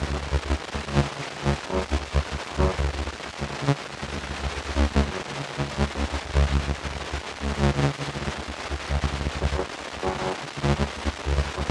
tesla-turret-base.ogg